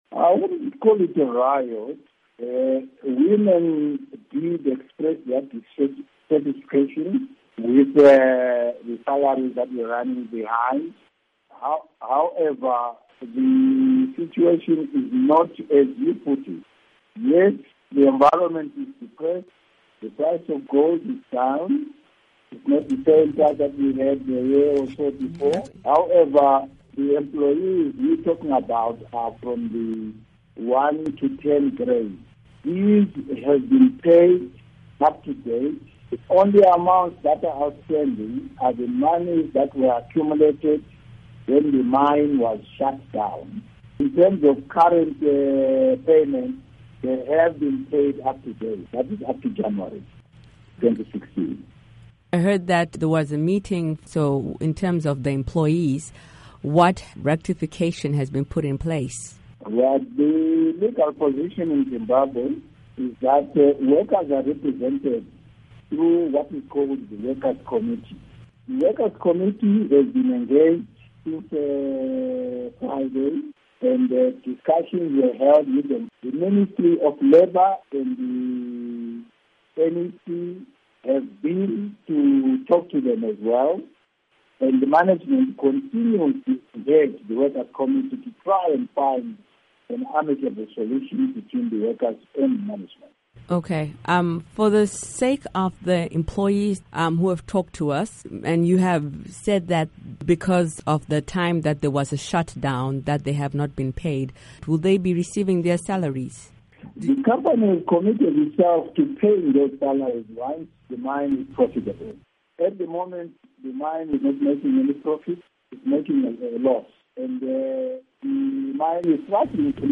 Interview with Ambassador Zenzo Nsimbi, Metallon Gold Zimbabwe